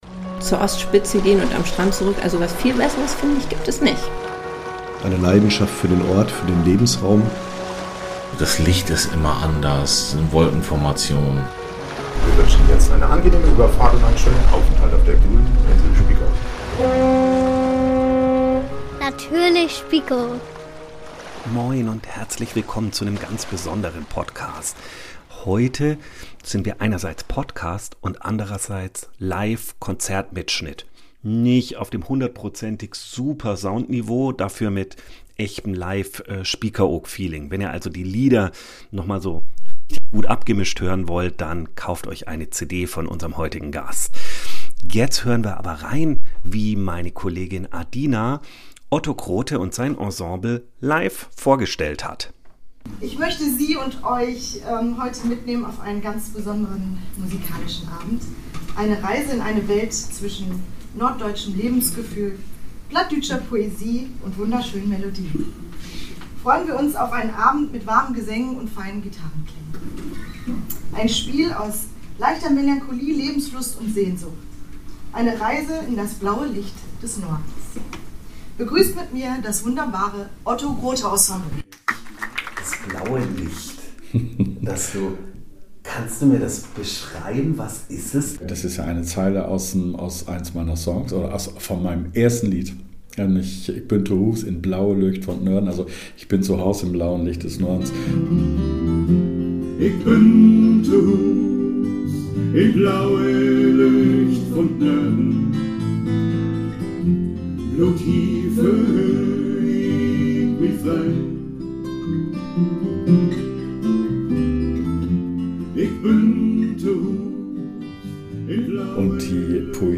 Ein persönliches Gespräch und stimmungsvolle Konzertmomente – für alle, die Spiekeroog lieben und Musik, die nachklingt wie salzhaltige Luft.